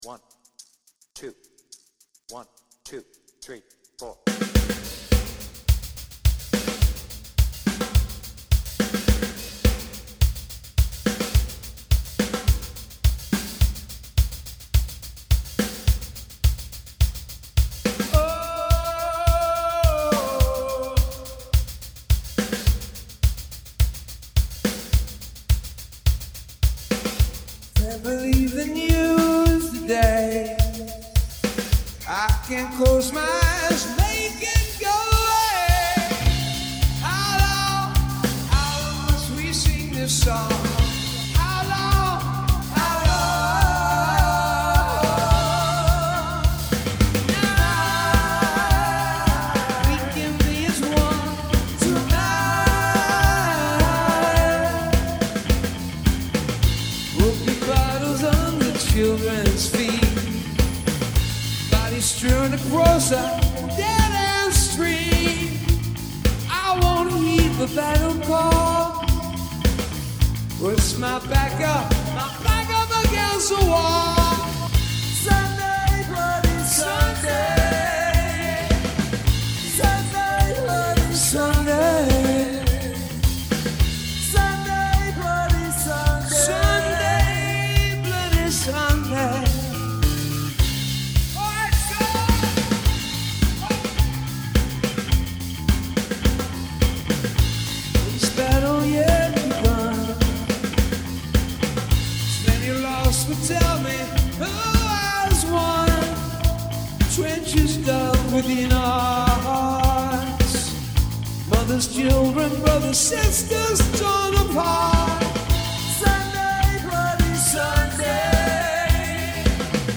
BPM : 106
Tuning : Eb
Based on the 360° version + extended outro